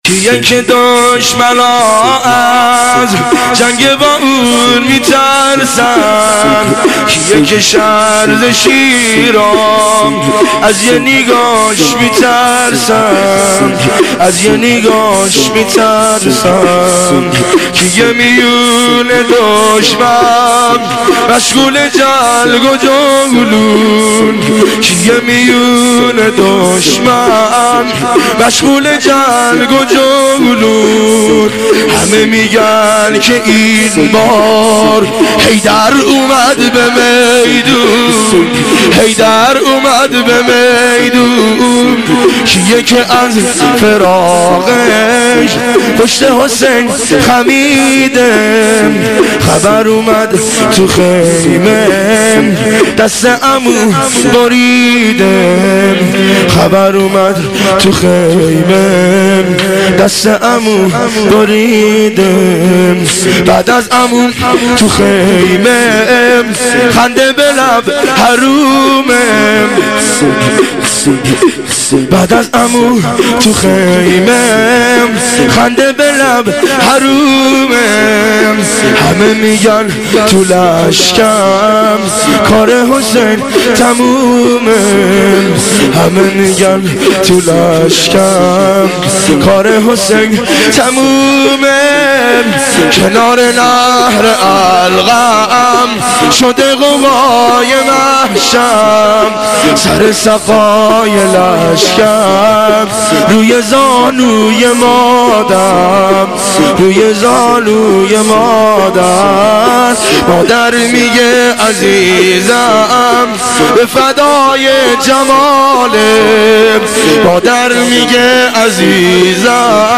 (شور - ابالفضل العباس سلام الله علیه)